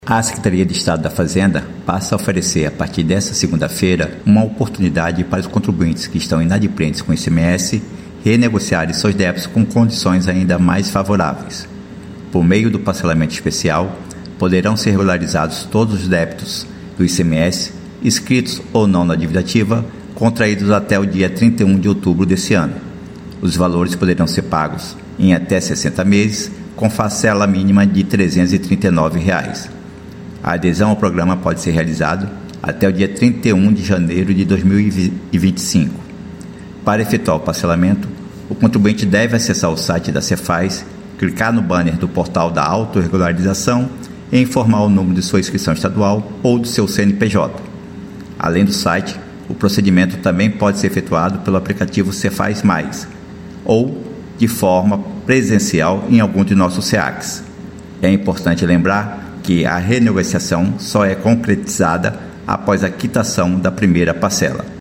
Compartilhe ALBERTO SCHETINE, SUBSECRETÁRIO DA RECEITA ESTADUAL